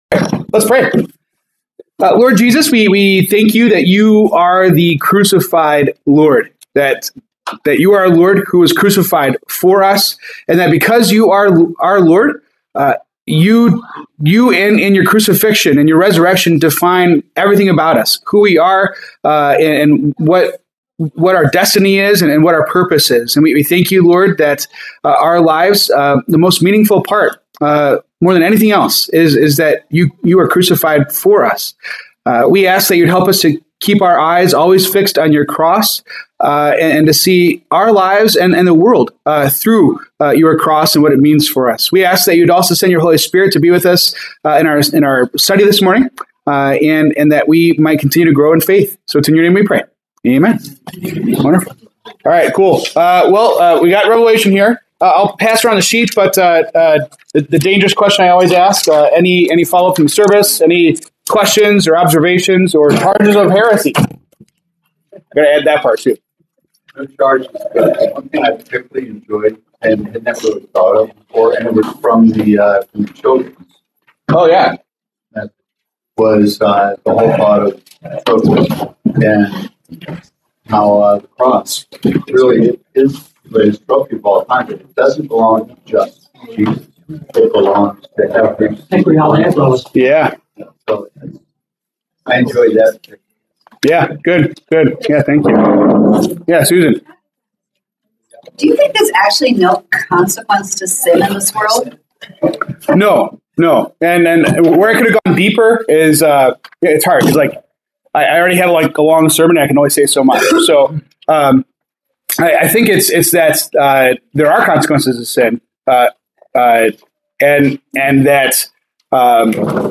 February 1, 2026 Bible Study
February 1 Sunday Morning Bible Study.m4a